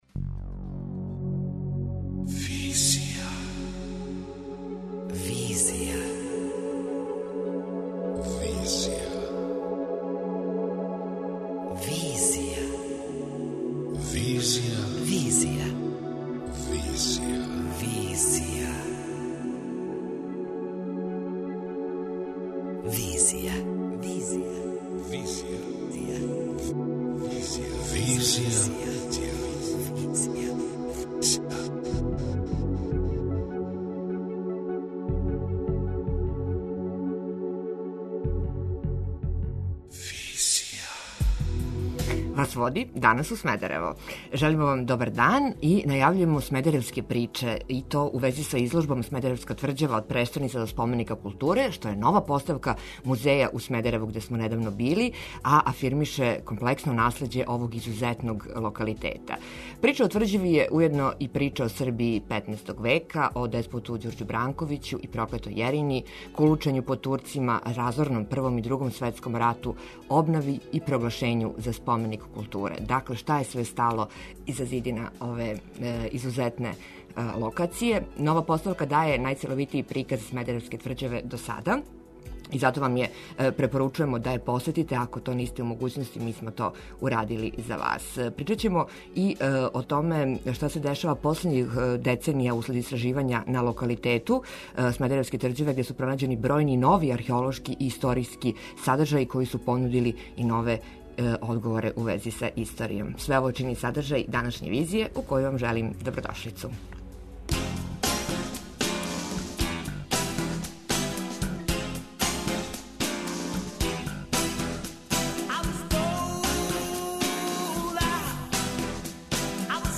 преузми : 26.99 MB Визија Autor: Београд 202 Социо-културолошки магазин, који прати савремене друштвене феномене.